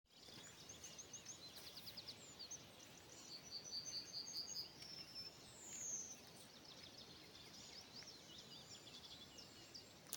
Dzeltenā stērste, Emberiza citrinella
StatussDzied ligzdošanai piemērotā biotopā (D)
PiezīmesEMBCIT x EMBLEU hibrīds. Aktīvi dzied izcirtumā.